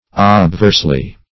\Ob*verse"ly\ ([o^]b*v[~e]rs"l[y^])